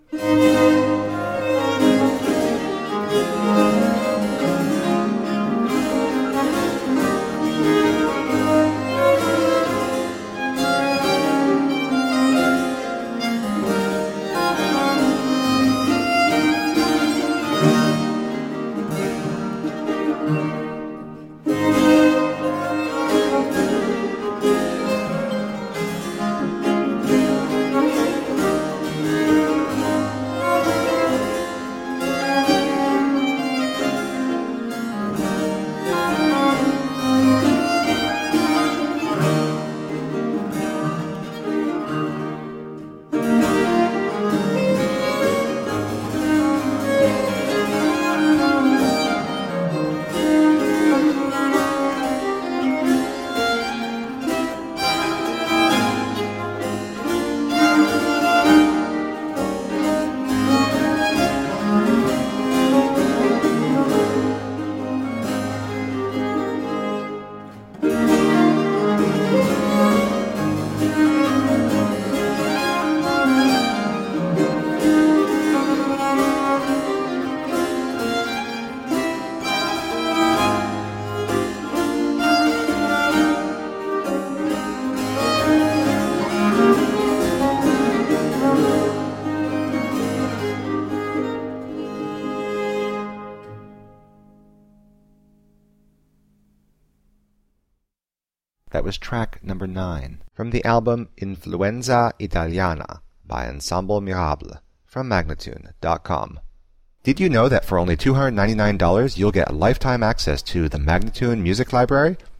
Rare and extraordinary music of the baroque.
using period instruments